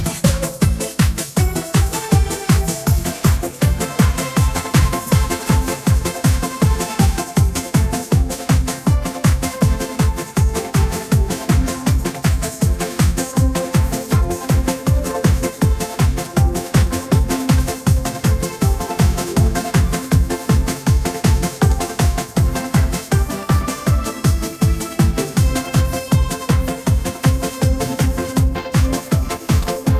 MusicGen stereo-medium model fine-tuned on Bbongjjak(뽕짝), the Korean modern electronic pop music genre, with the text token 'Bbongjjak'.
"Bbongjjak"